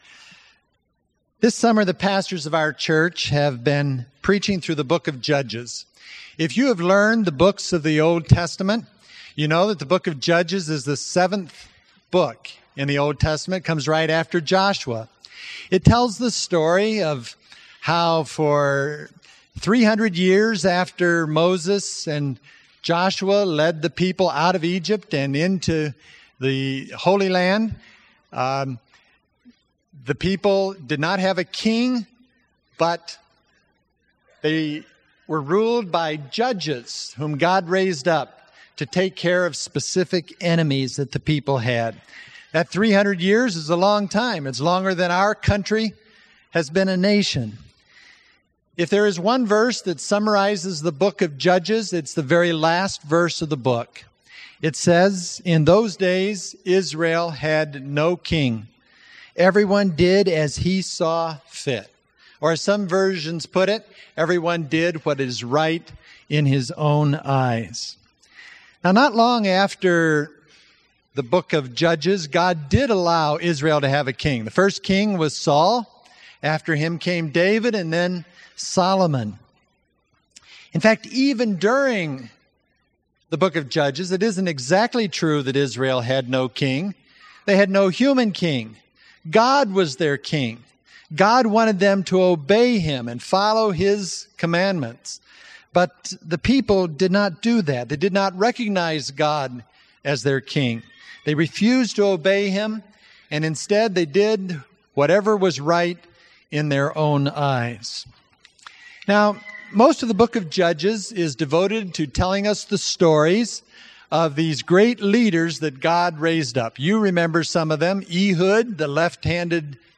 Today the children will experience a service that is more like what your parents normally attend, because we’re going to have a sermon and then we will share communion together.